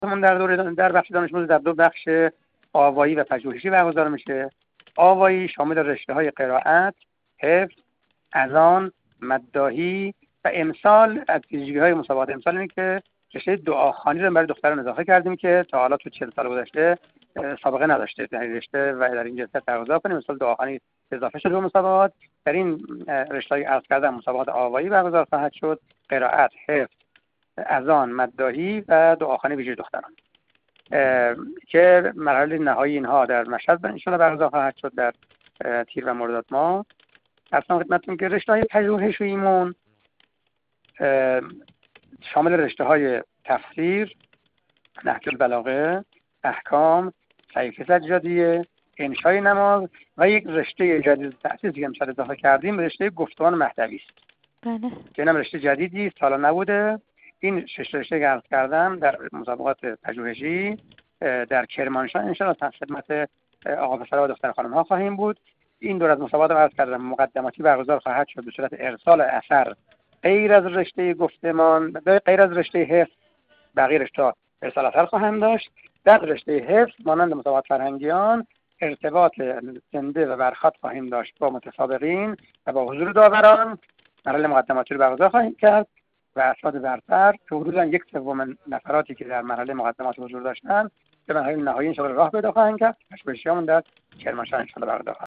میکائیل باقری، مدیرکل قرآن، عترت و نماز وزارت آموزش و پرورش در گفت‌وگو با ایکنا، ضمن بیان این مطلب گفت: چهل و یکمین دوره مسابقات قرآن، عترت و نماز دانش‌آموزی در دو بخش پژوهشی و آوایی از مهرماه در مدارس شروع شده است و دانش‌آموزان امکان شرکت در دو رشته را داشتند.